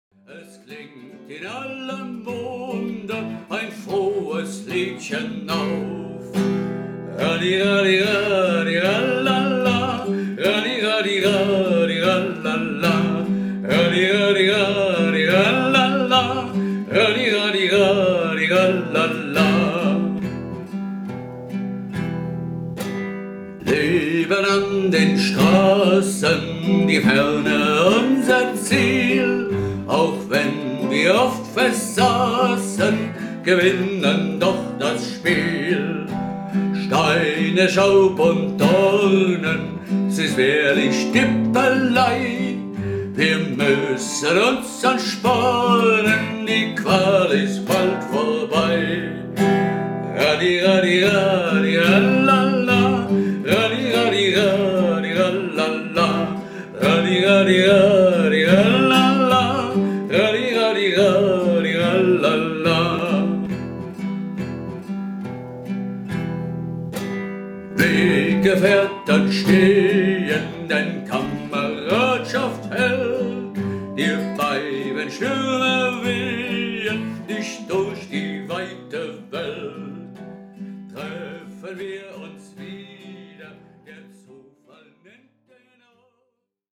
[3 Doppelstrophen]